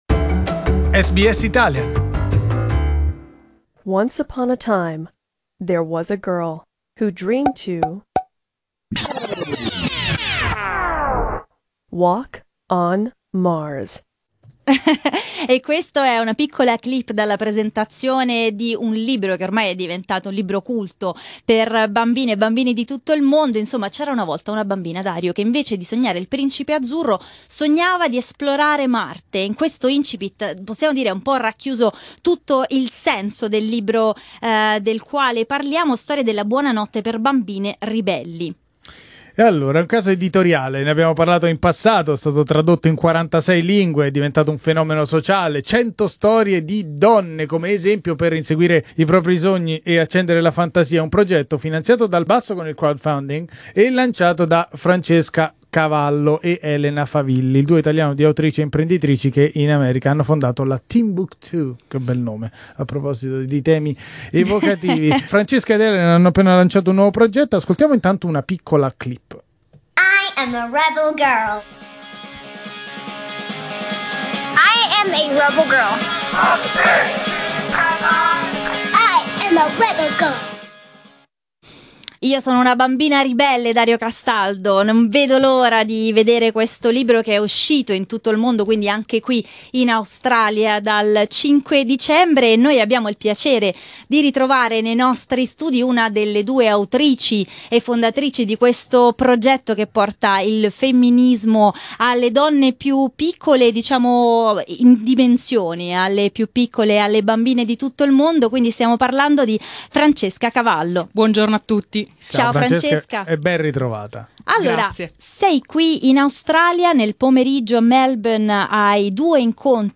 Francesca Cavallo è venuta a trovarci nei nostri studi per raccontarci come è nato il progetto e come si diventa una 'bambina ribelle'.